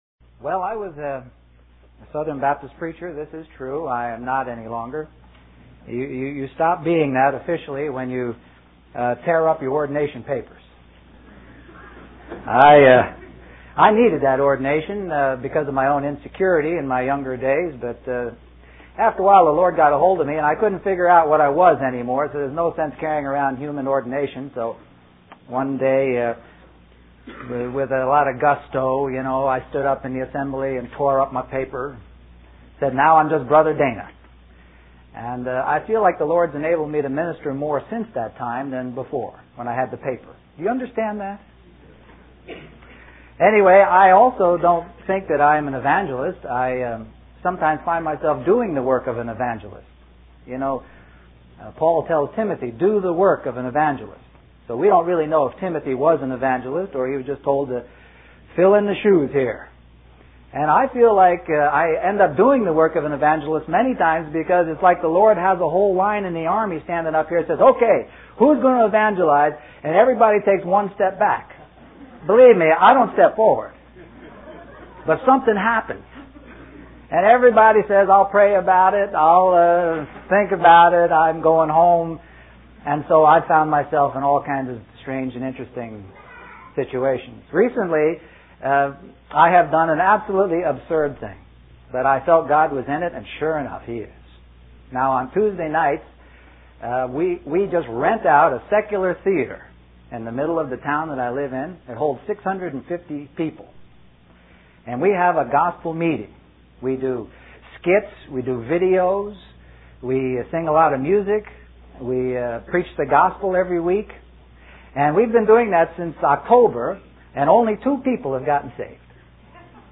Richmond, Virginia, US